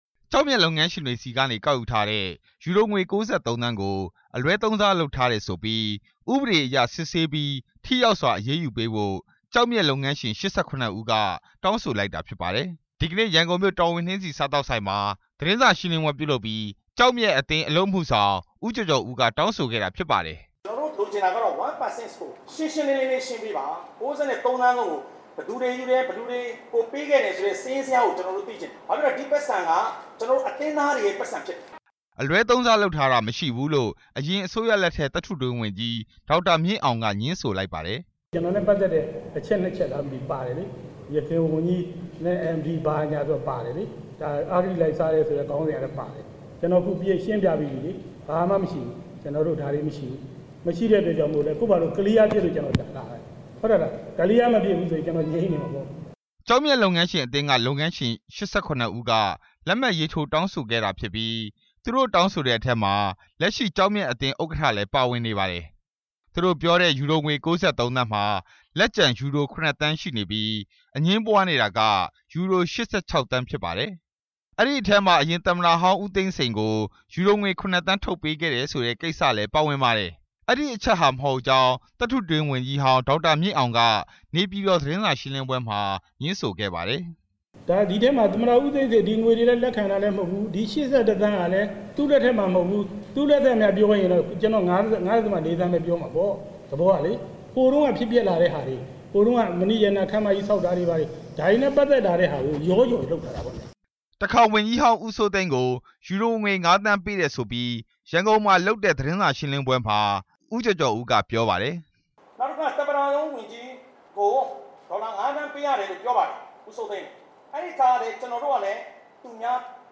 နှစ်ဘက်သတင်းစာရှင်းလင်းပွဲက အချက်တွေကို ကောက်နှုတ်တင်ပြထား ပါတယ်။